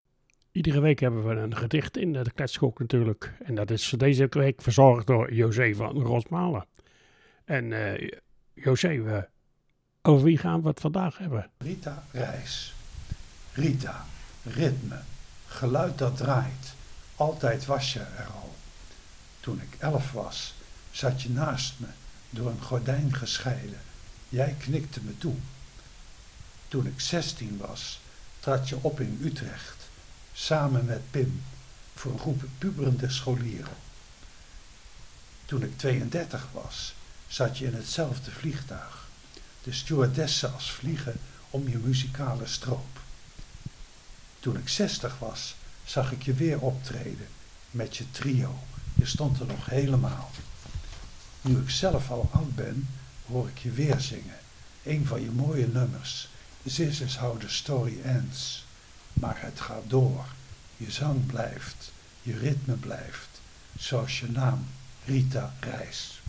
Gedicht